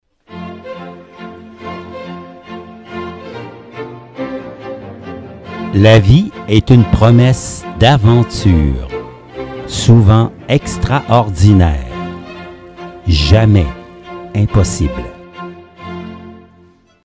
Musique de fond; Le canon de Pachelbel ( violons en 432 htz )
( la qualité sonore est variable… )